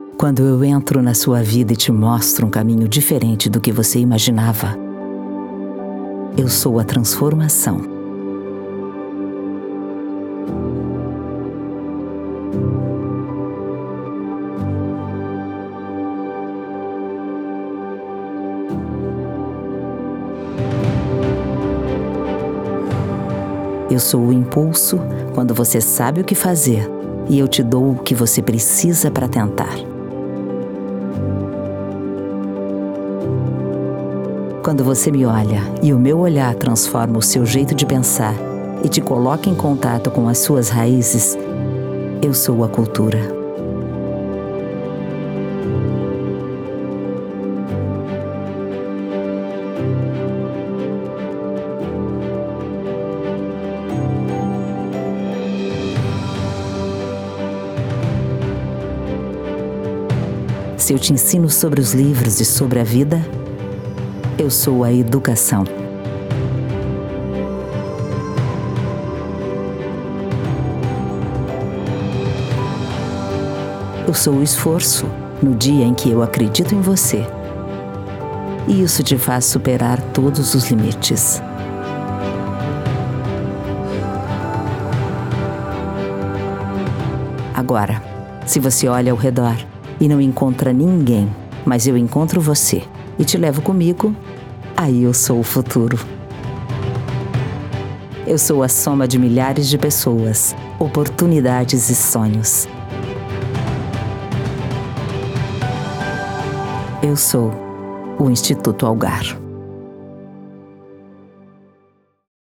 Clássico / Instituo Algar
Voz Padrão - Grave 01:52
Owns an at home recording studio.